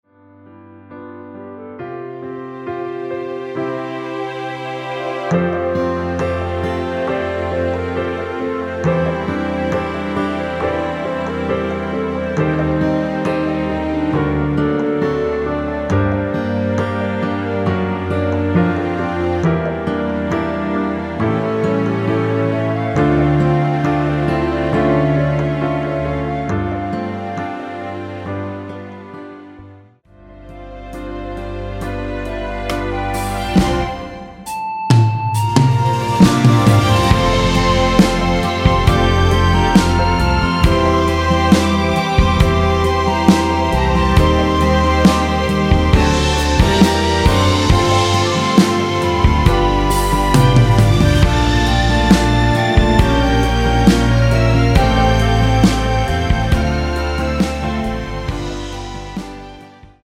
전주가 길어서 미리듣기는 중간 부분 30초씩 나눠서 올렸습니다.
원키에서(+1)올린 멜로디 포함된 MR입니다.
◈ 곡명 옆 (-1)은 반음 내림, (+1)은 반음 올림 입니다.